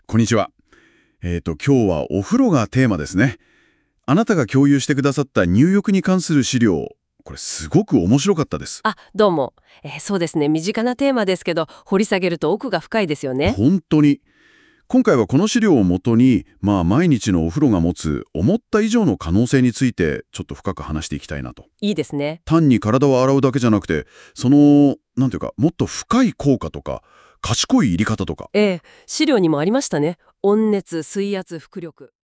先日追加された機能として、音声概要(日本語)があります！
ラジオパーソナリティが実際に喋っているかのよう！今風にいえばポッドキャスト番組のようですね！
単純に読み上げているのではなく、ちゃんとした会話の間やスピードの緩急があると思いませんか？
もちろん読み間違いはあるのですが、Google翻訳の日本語音声読み上げをネタにしていた時代を考えると随分進化しましたよね…
NotebookLMで実際に生成した音声冒頭30秒.wav